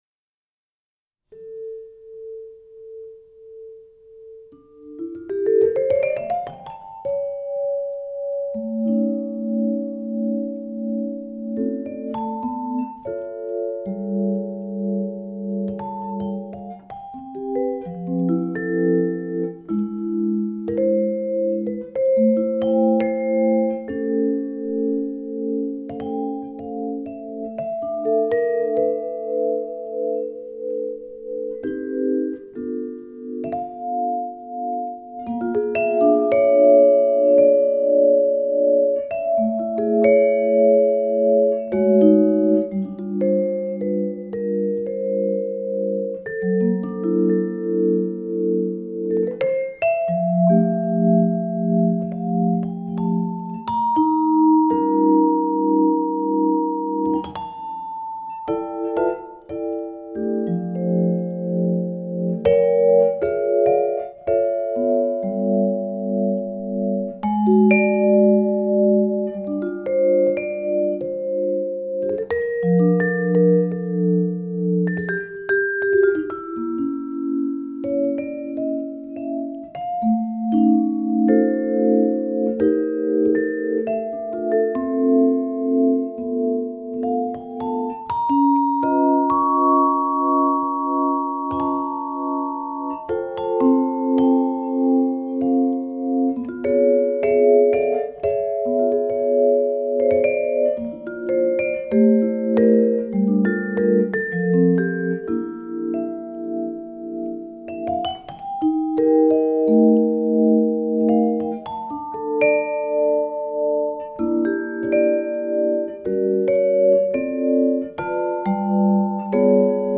Microphone K4 Stereo AB 30cm,96khz,24bit